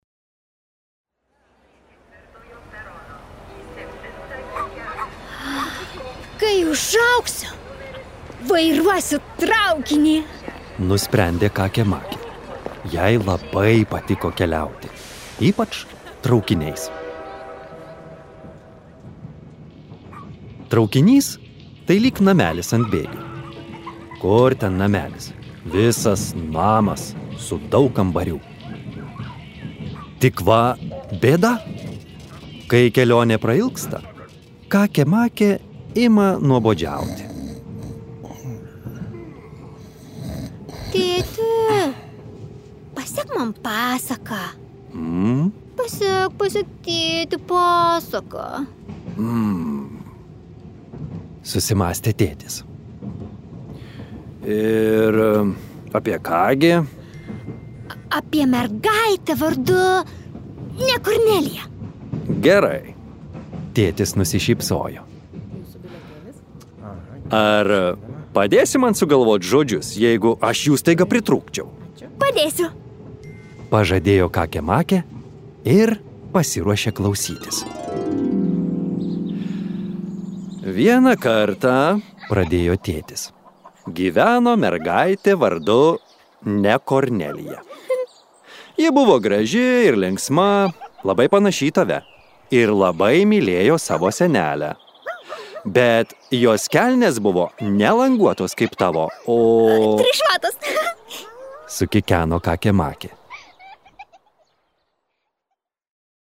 Kakė Makė ir magiška kelionė | Audioknygos | baltos lankos